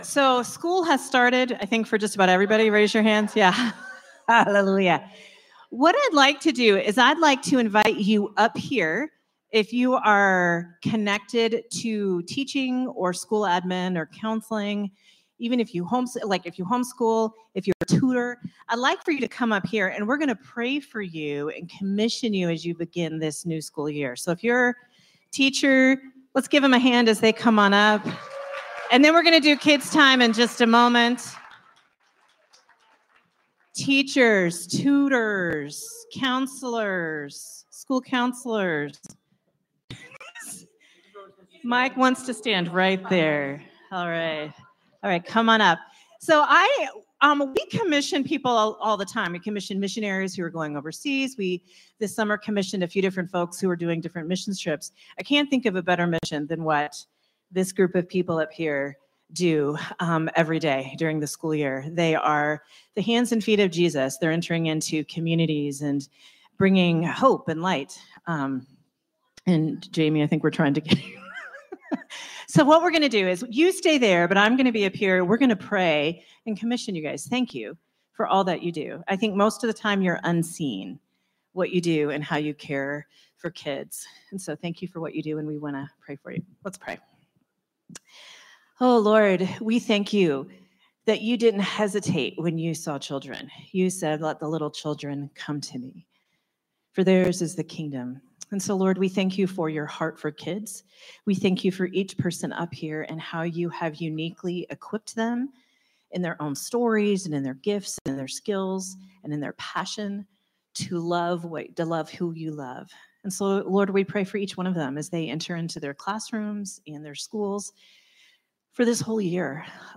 Sermon from Celebration Community Church on August 24, 2025